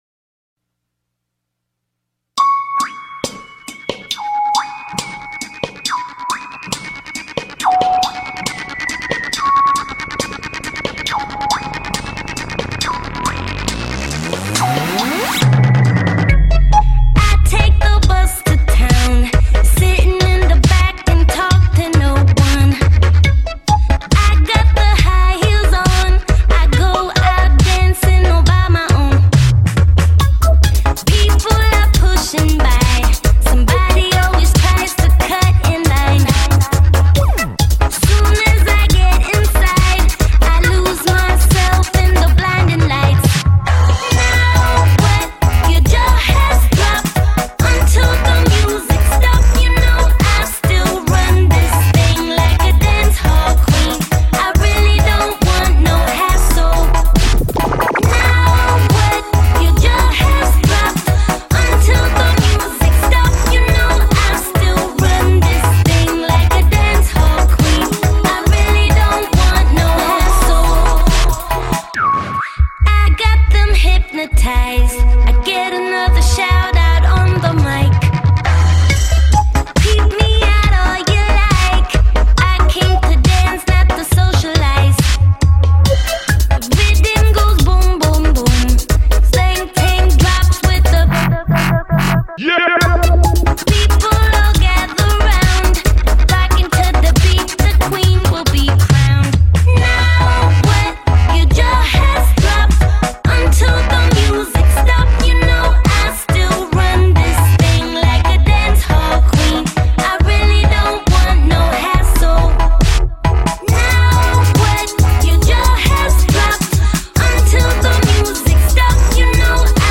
going a little dancehall on us